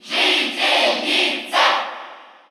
File:Villager Female Cheer Russian SSBU.ogg
Villager_Female_Cheer_Russian_SSBU.ogg